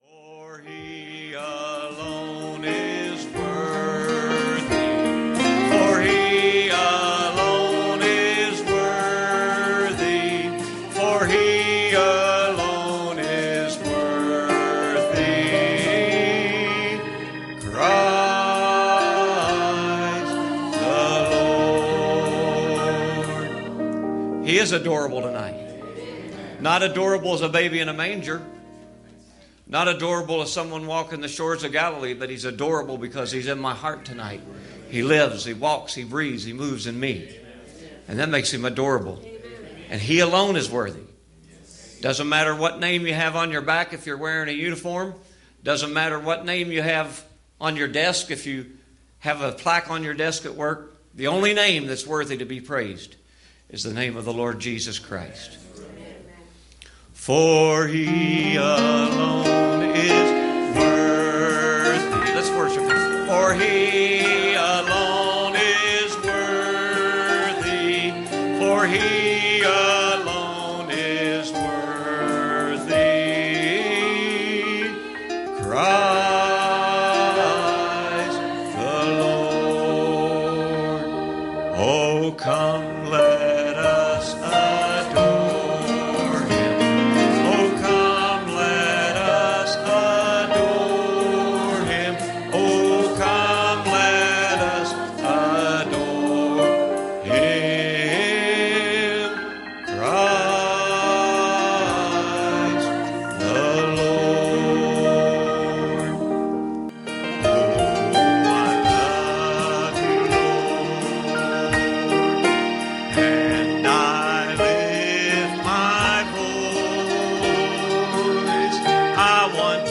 Passage: 2 Kings 4:25 Service Type: Wednesday Evening